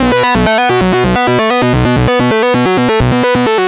Kitch N Sync B 130.wav